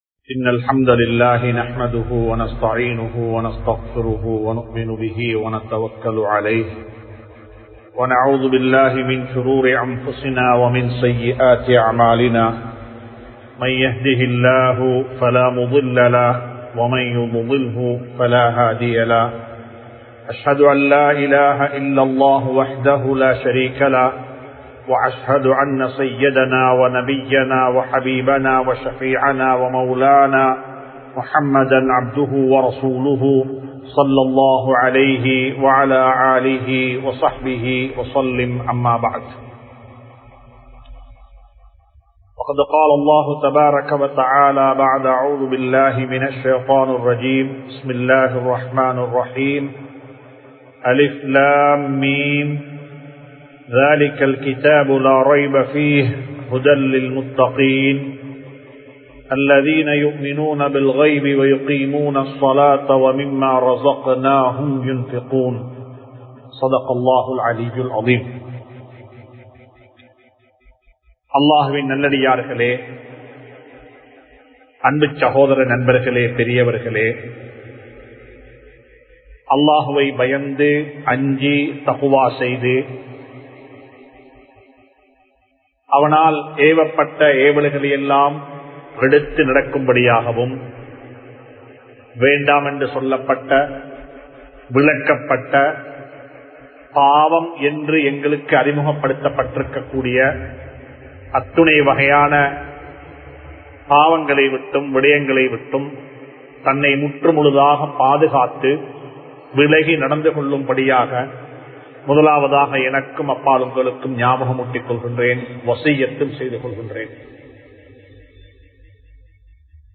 நாம் மறந்த பொறுப்புகள் | Audio Bayans | All Ceylon Muslim Youth Community | Addalaichenai